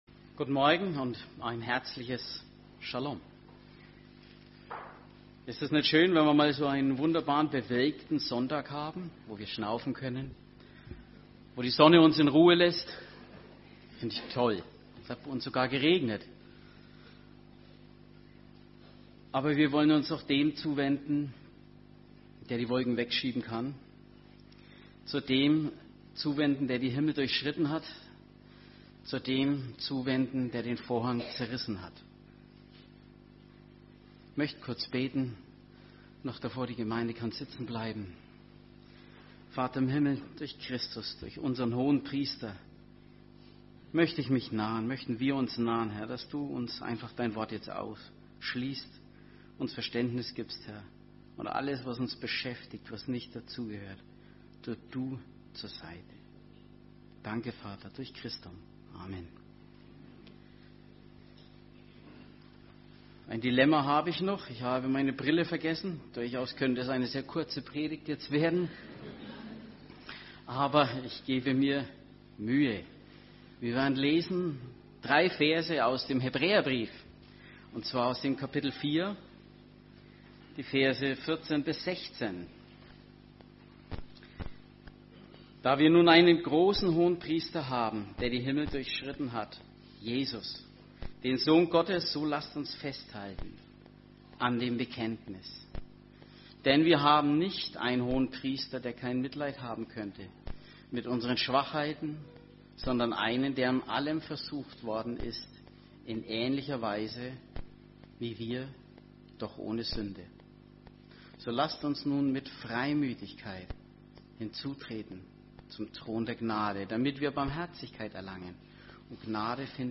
Am Sonntag, den 30. Juli begann unsere neue Predigtreihe zum Thema: „Gnade“.